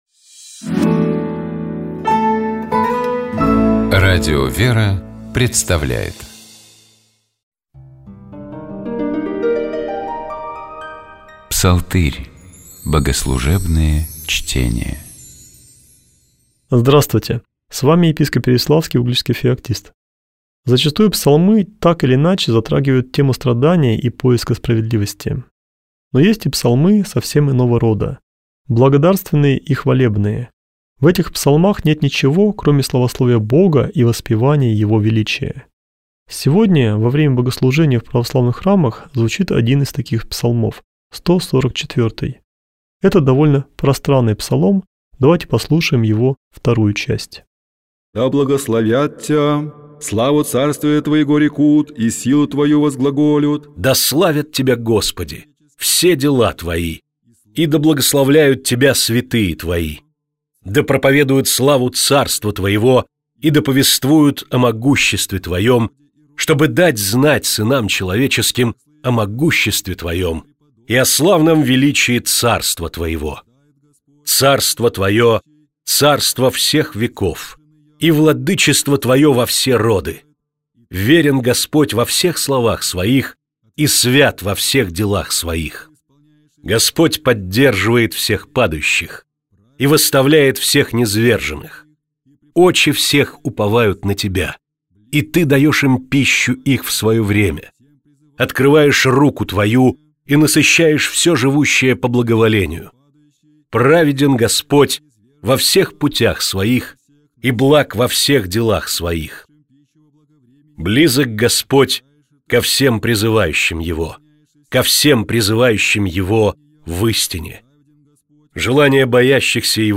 Узнаем в ходе сегодняшней экскурсии.